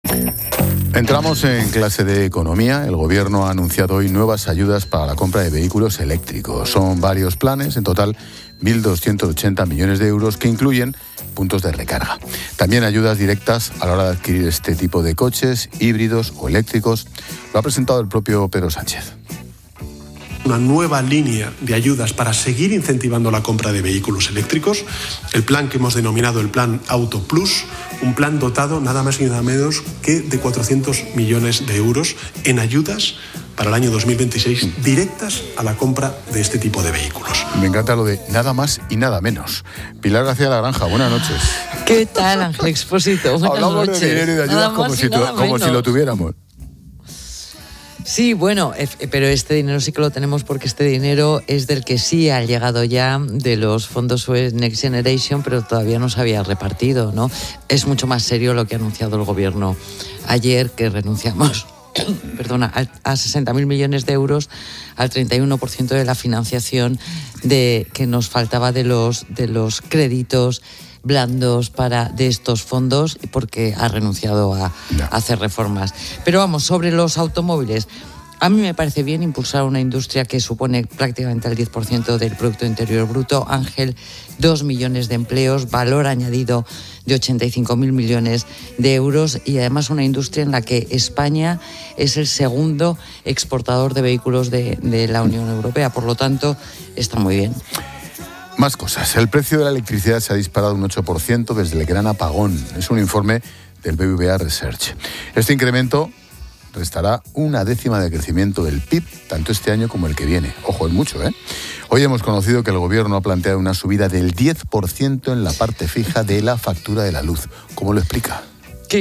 Ángel Expósito y la experta económica y directora de Mediodía COPE, Pilar García de la Granja, analizan las nuevas ayudas para la compra de vehículos eléctricos